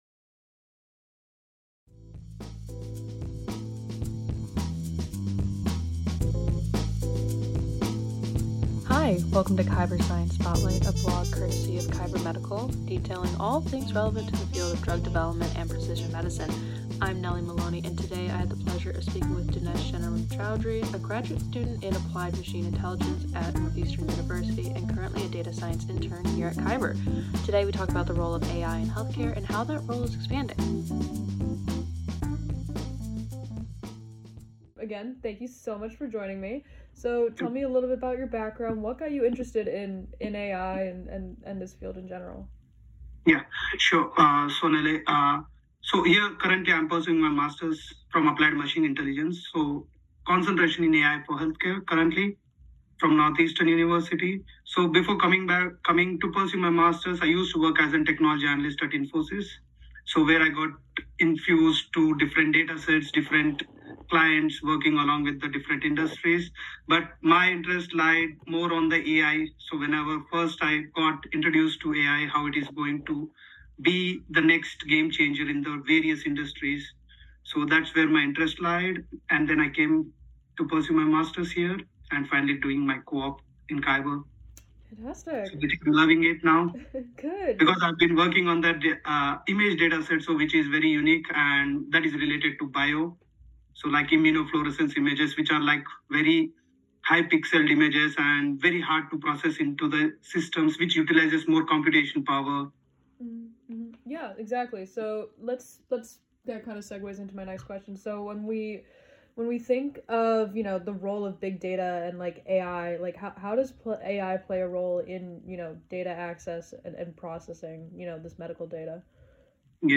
In this interview, they discuss the current role of AI in healthcare, what the future of healthcare can look like with AI, and more.